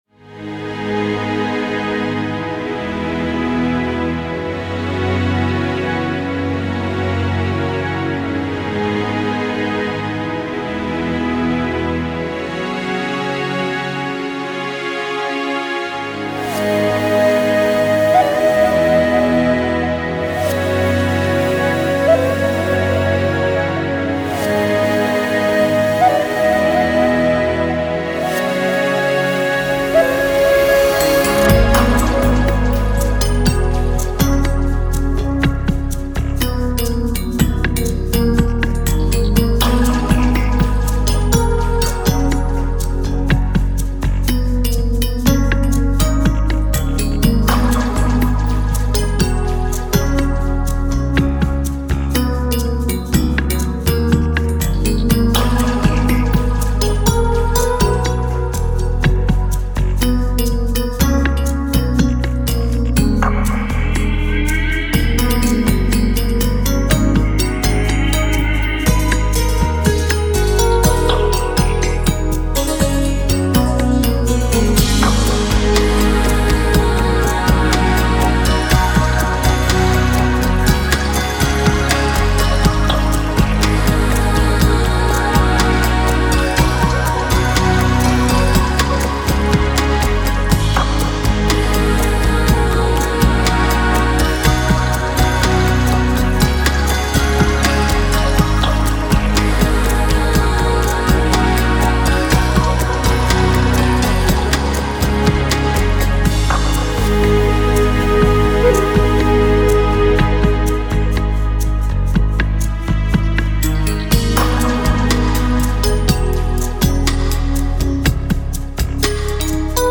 Electronic, New Age